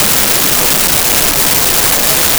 Restaurant Indoor
Restaurant Indoor.wav